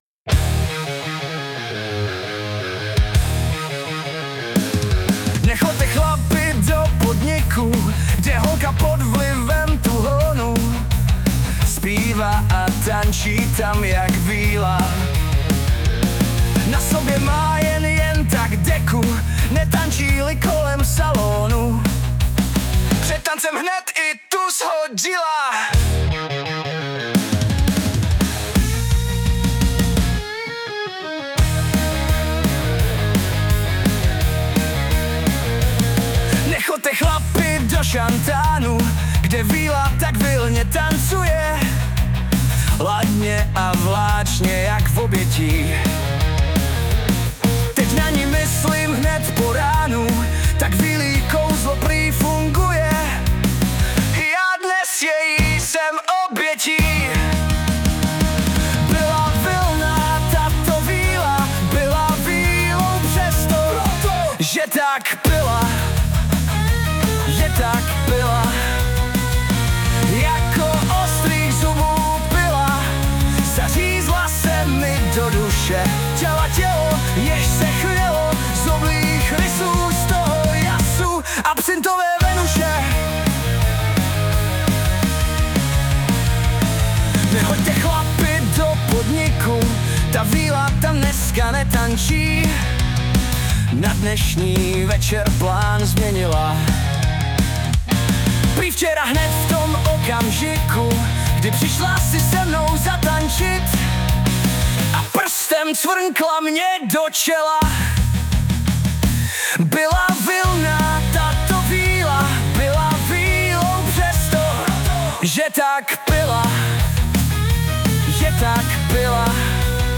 Anotace: Starší báseň (i ta tu je) přepsána a doplňena do písňového textu a pak převedena do podoby hotové písně pomocí SUNO AI.